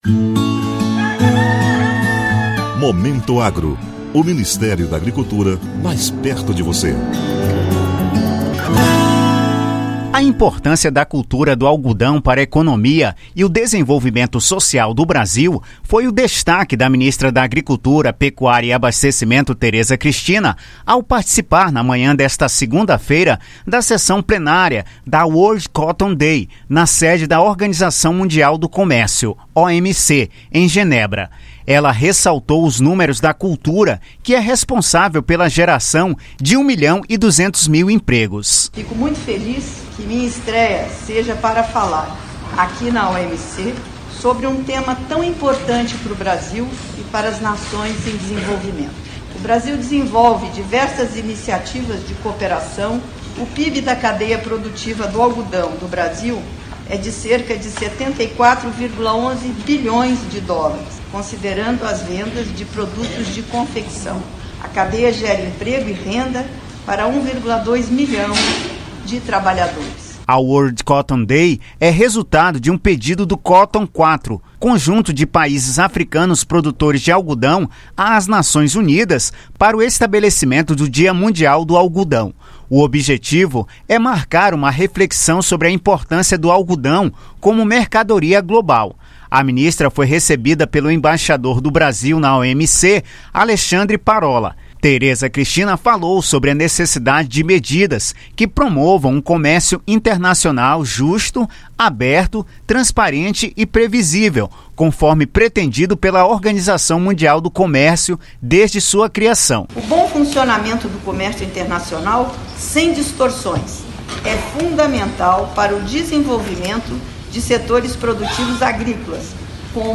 Em discurso na OMC, ministra destaca papel econômico e social do cultivo do algodão